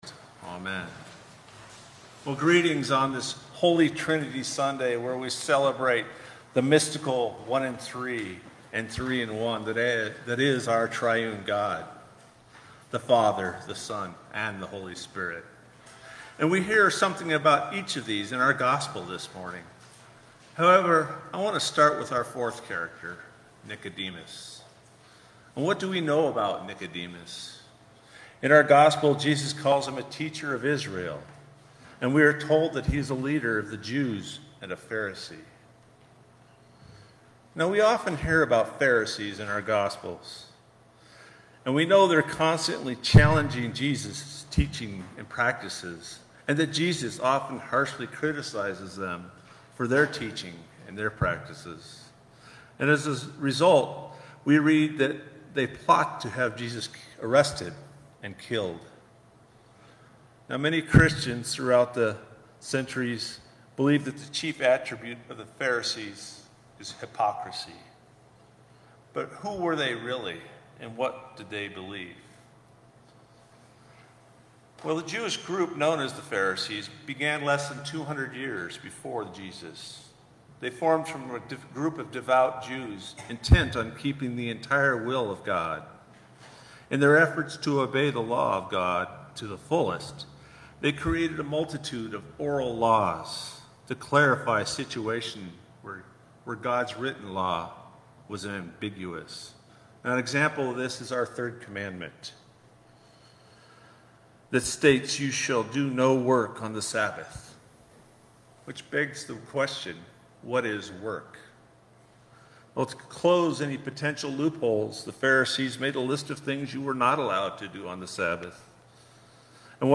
Sermons | Bethlehem Lutheran Church